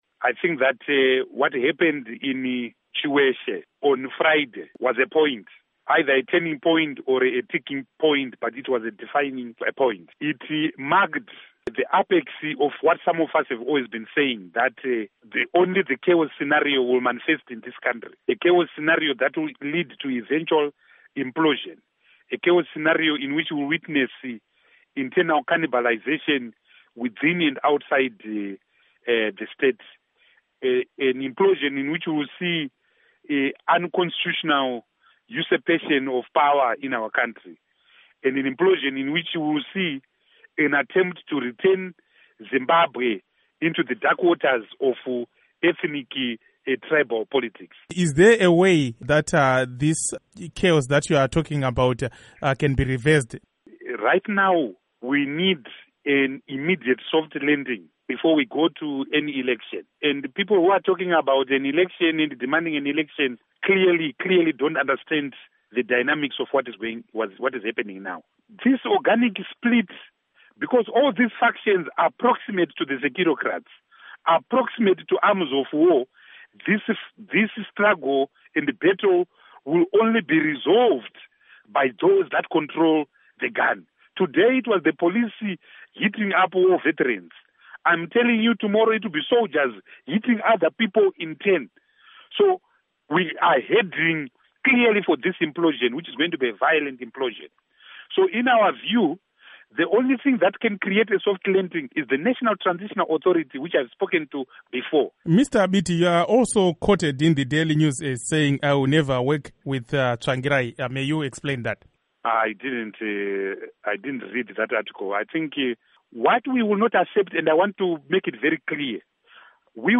Interview With People's Democratic Leader, Tendai Biti